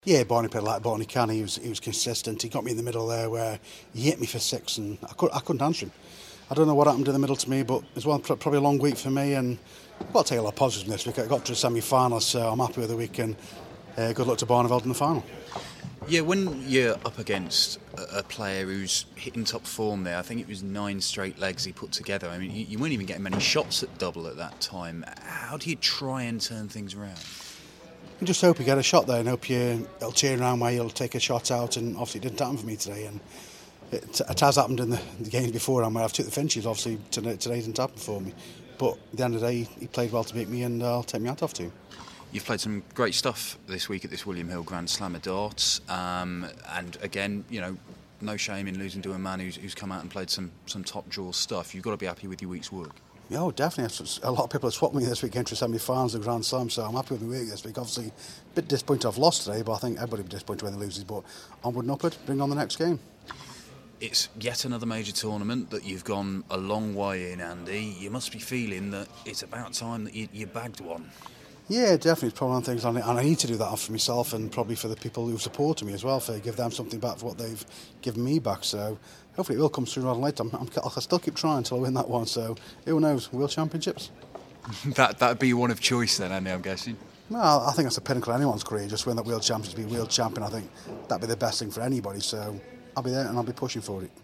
William Hill GSOD - Hamilton Interview (SF)